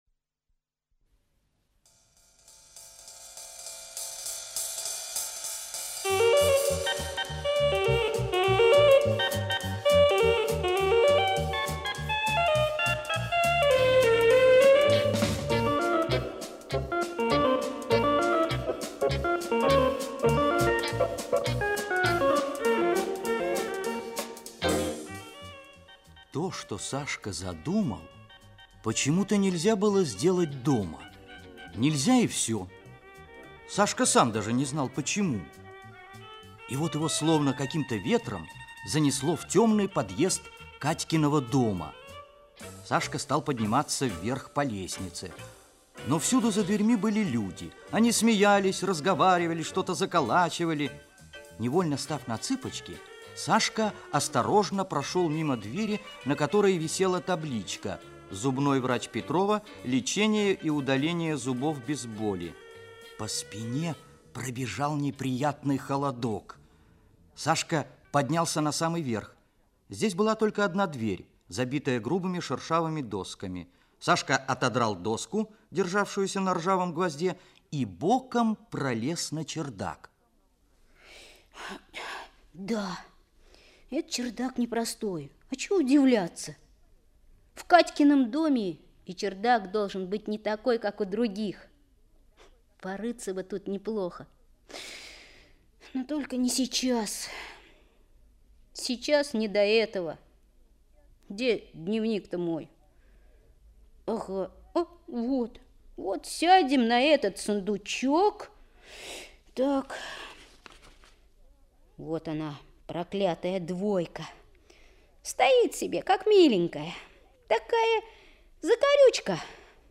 На старом чердаке - аудиосказка Прокофьевой С.Л. Однажды Саша Кукушкин нашел на старом чердаке древнюю Волшебную энциклопедию.